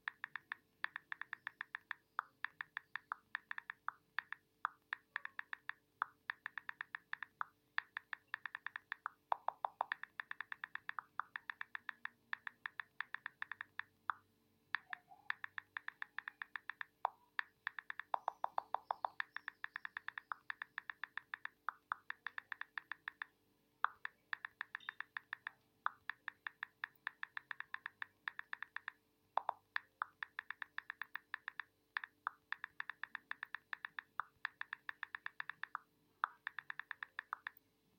Phone Typing